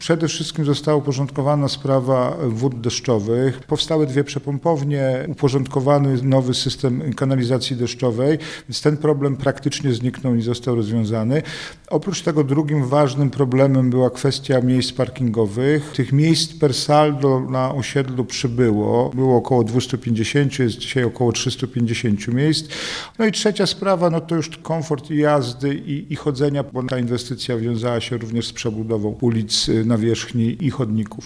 – Osiedle powstało 50 lat temu i od tej pory infrastruktura nie była remontowana – powiedział Jacek Milewski, prezydent Nowej Soli: